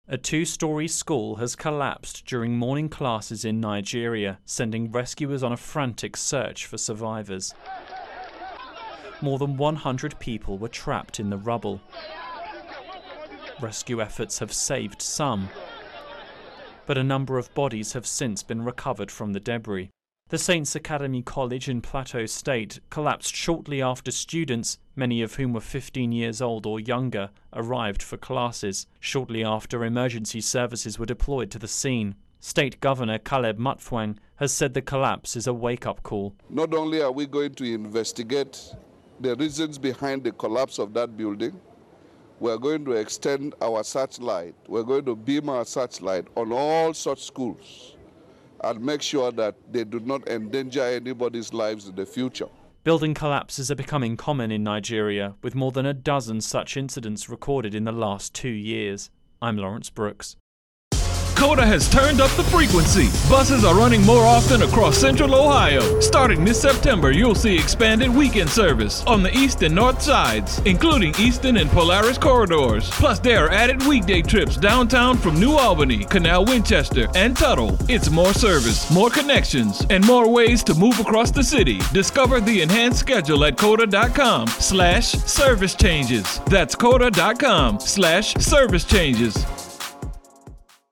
reports on a school collapse in Nigeria that has killed around two-dozen students, with fears many more victims will be found.